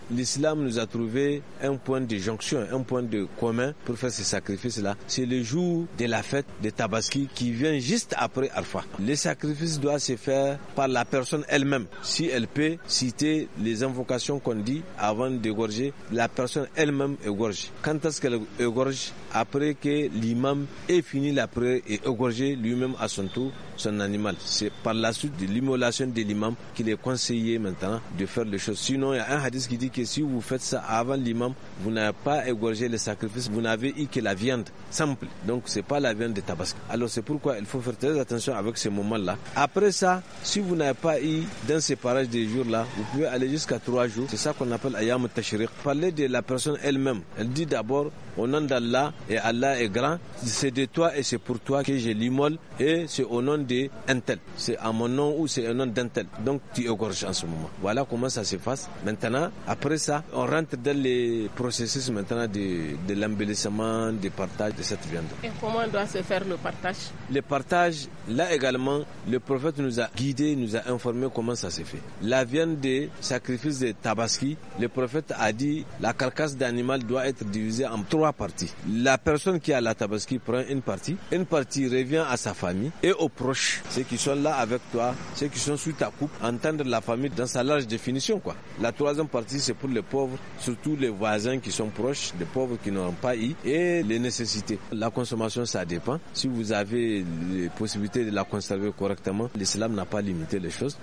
Magazine en français